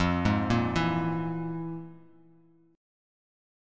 FmM7bb5 Chord
Listen to FmM7bb5 strummed